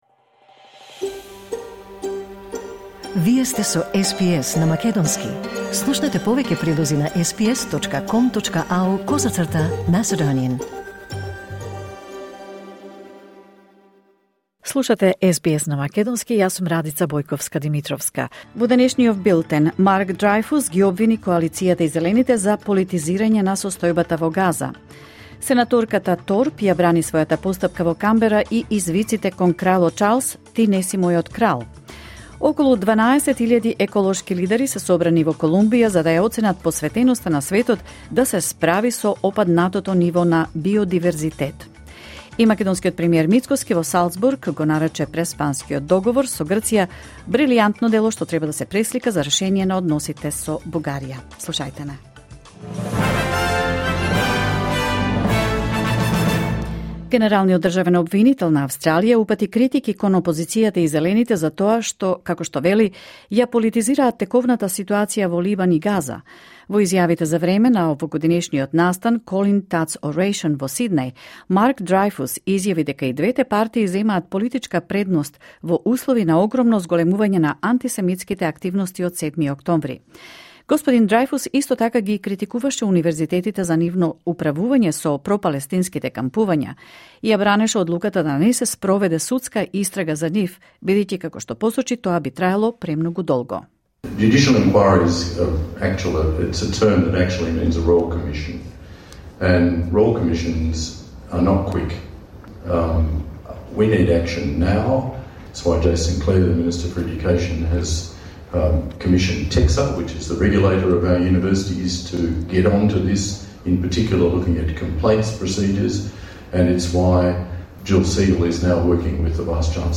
SBS News in Macedonian 22 October 2024